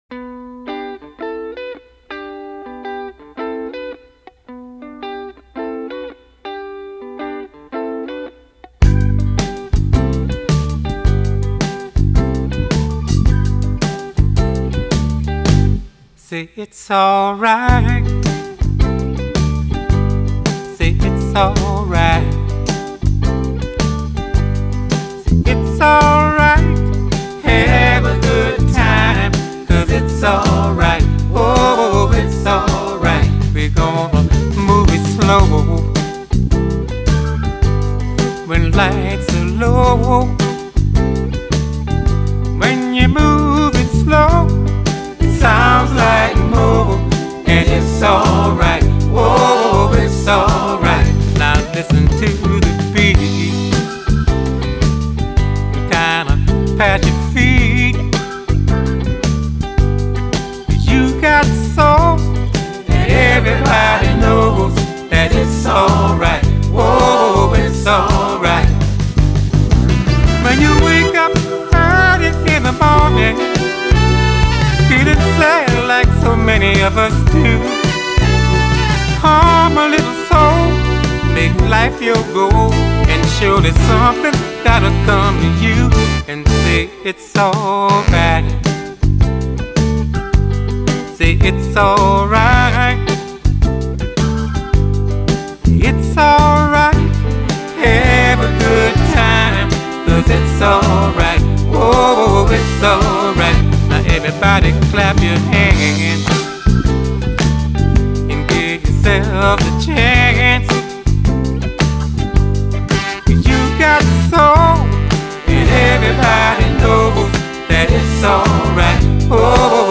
Genre: Soul